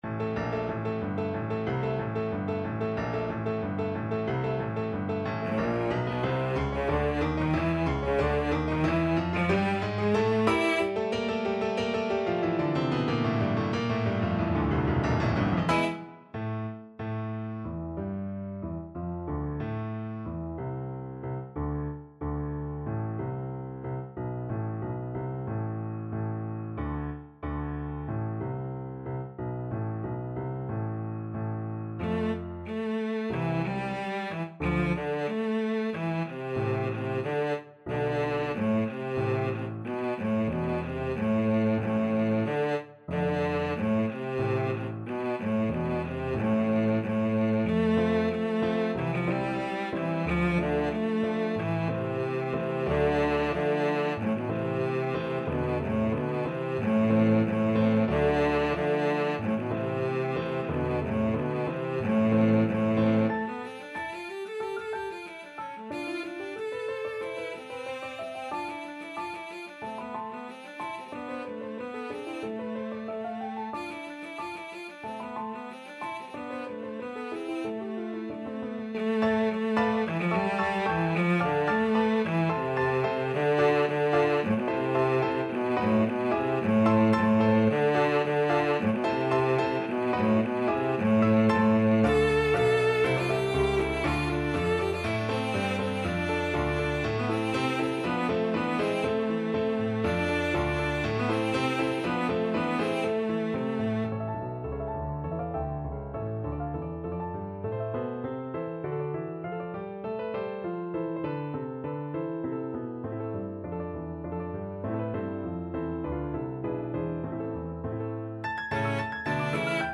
Classical Glière, Reinhold Russian Sailors' Dance Cello version
Cello
A minor (Sounding Pitch) (View more A minor Music for Cello )
2/4 (View more 2/4 Music)
Allegro =92 (View more music marked Allegro)
Classical (View more Classical Cello Music)